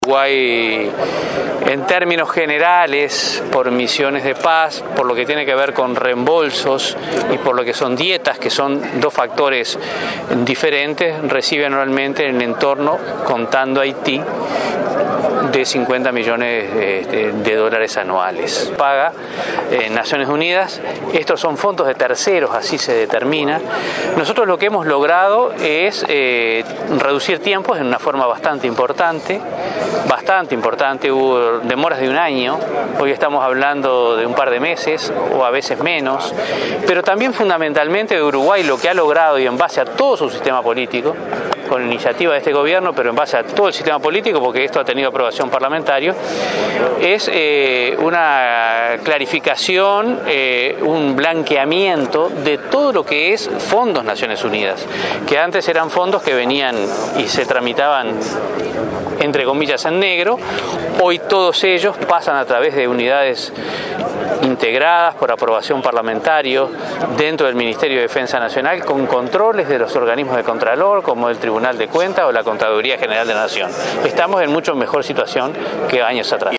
Los tiempos de pago de Naciones Unidas al país se redujeron de casi un año a dos meses, apuntó el jerarca a la prensa, tras el acto de homenaje a los efectivos que estuvieron en Haití. Dijo que el país logró además, en base a todo el sistema político, una clarificación de los fondos que llegan desde el organismo internacional.